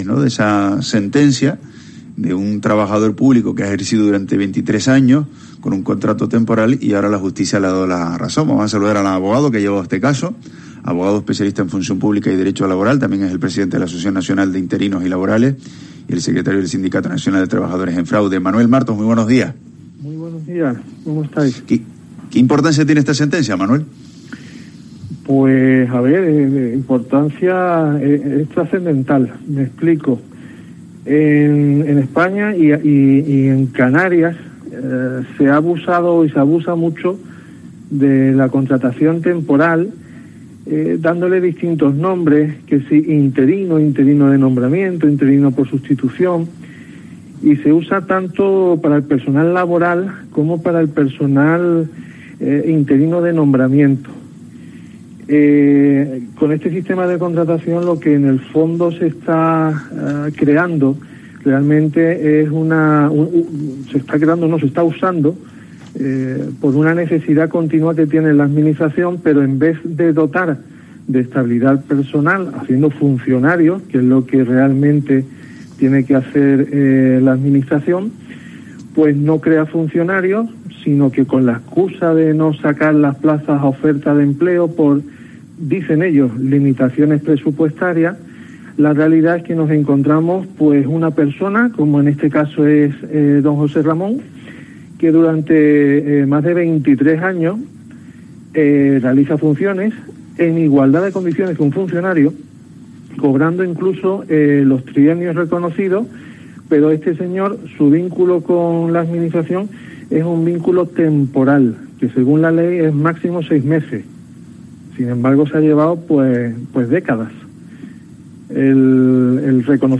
Por los micrófonos de COPE Canarias ha pasado